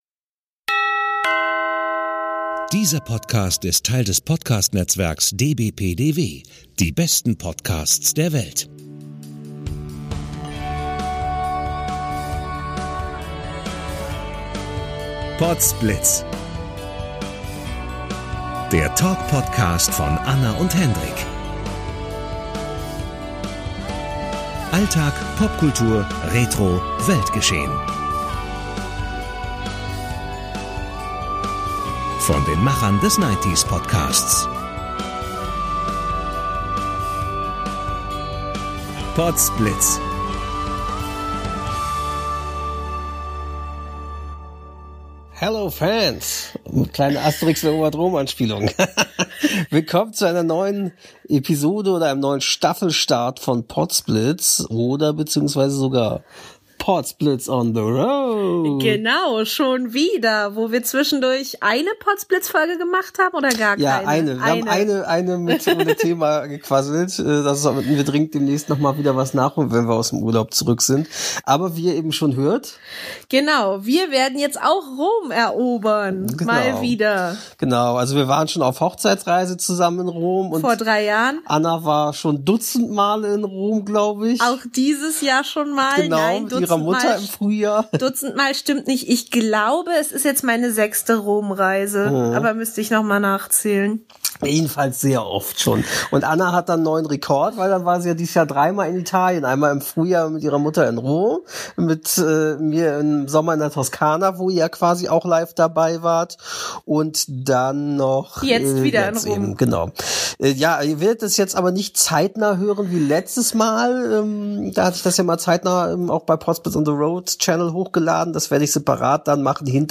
- Und ihr seid wie immer quasi fast live dabei in diesem PLOG, einem Reisetagebuch in Podcast-Form.